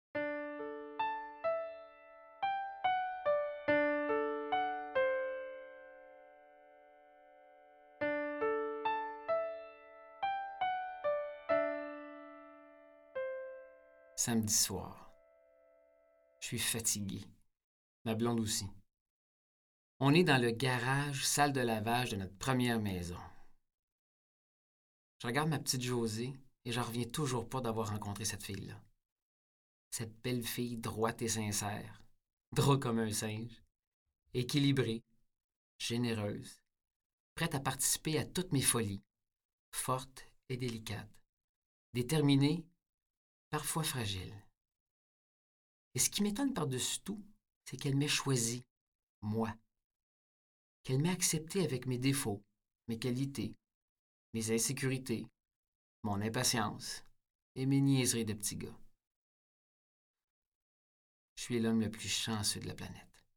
Extrait gratuit - Mon voyage de pêche de Jean-Marie Lapointe
Ce jour-là, malgré les blessures et l’aigreur des incompréhensions mutuelles, tous deux finirent par s’avouer l’un à l’autre qu’ils s’étaient aimés, qu’ils s’aimaient encore et qu’ils s’aimeraient toujours. Lu par Jean-Marie Lapointe